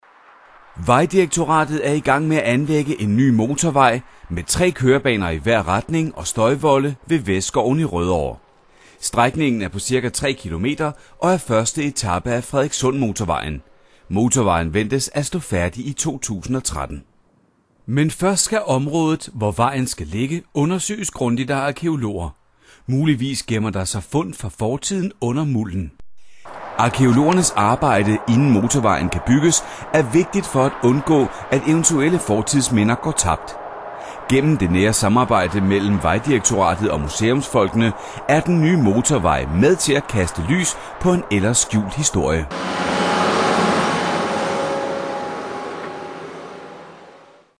I am a male speaker living in Denmark.
Sprechprobe: Sonstiges (Muttersprache):
Danish voice over talent.